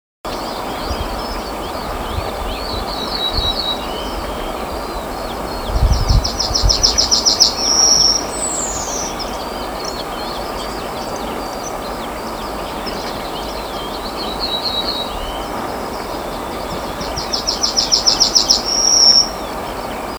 садовая овсянка, Emberiza hortulana
СтатусПоёт
ПримечанияUzturas un dzied kopā ar dzeltēno stērsti.